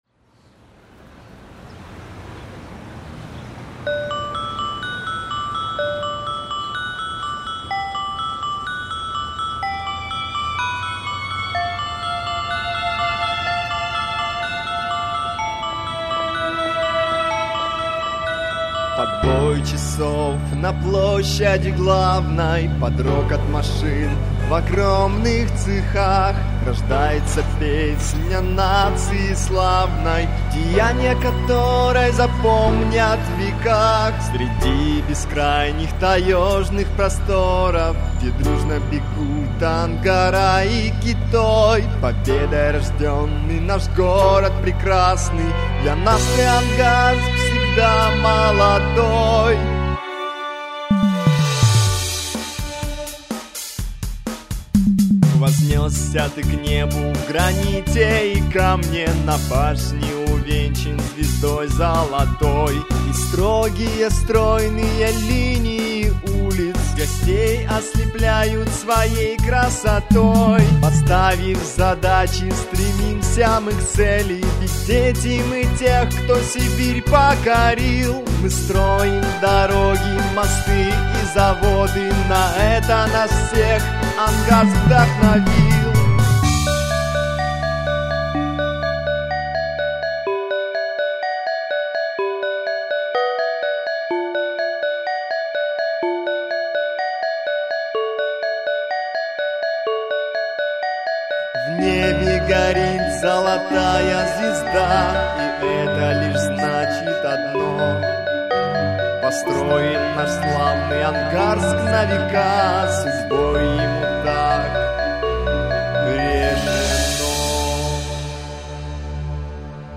просьба помидорами не кидаться, записывали не профессионалы, но мне понравилось